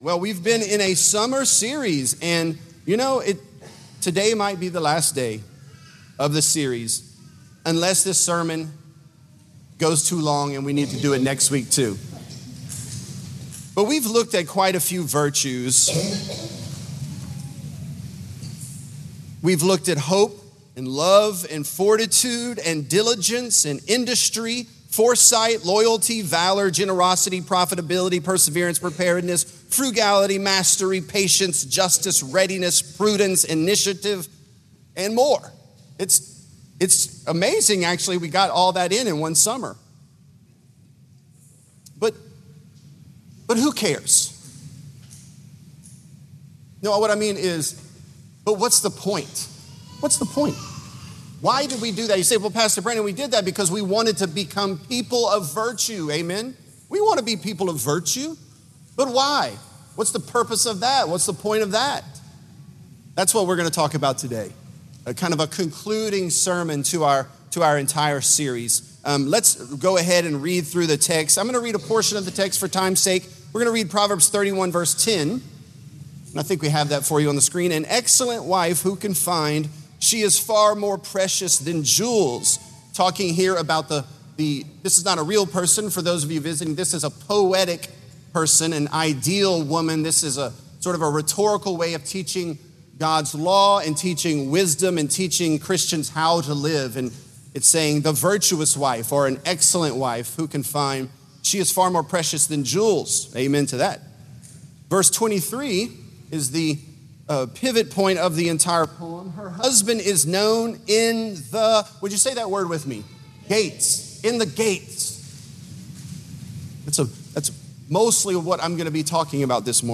Virtuous: The Purpose of Virtue | Lafayette - Sermon (Proverbs 31)